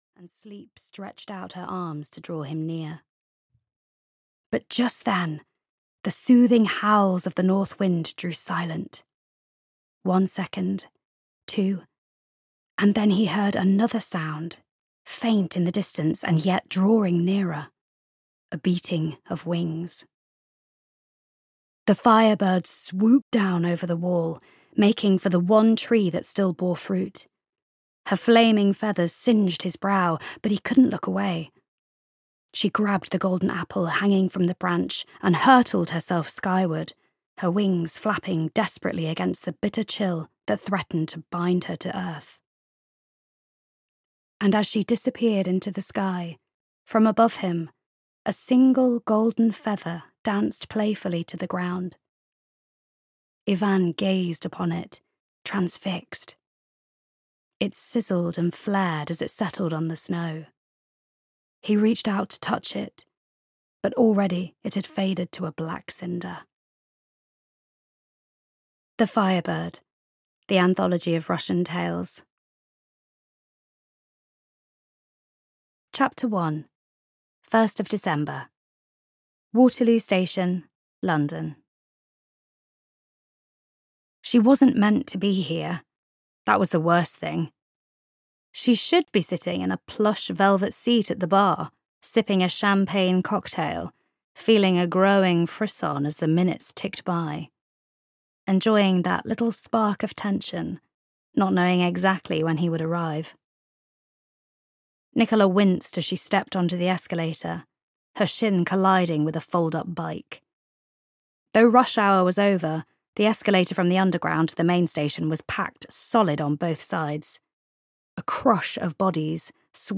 Moonlight on the Thames (EN) audiokniha
Ukázka z knihy